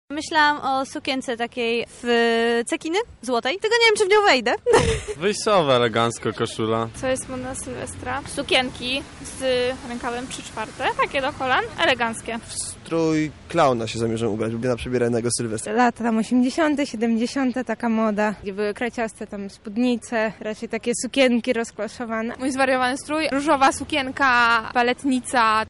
Zapytaliśmy mieszkańców Lublina w czym oni zamierzają pożegnać stary i powitać nowy rok.